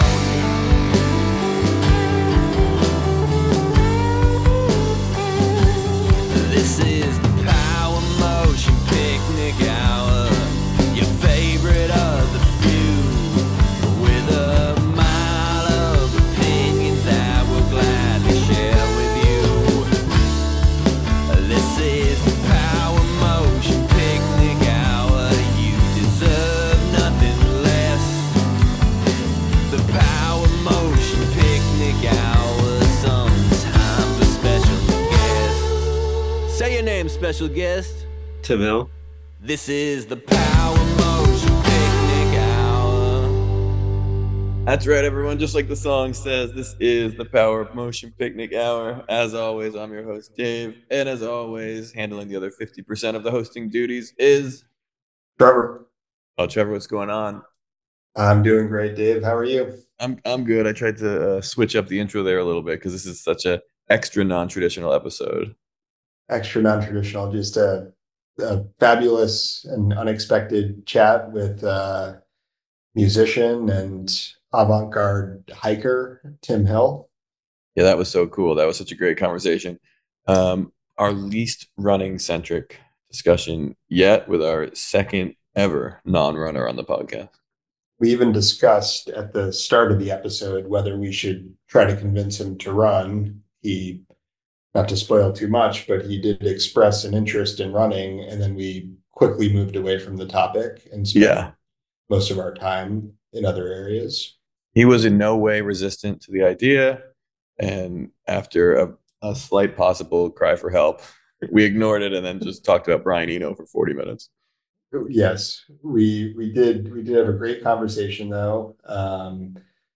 The hosts spend a majority of their time with him variously mispronouncing words and talking about Brian Eno. Important race recaps are also covered amidst a traditional opening of the mailbag and receipt of a correction.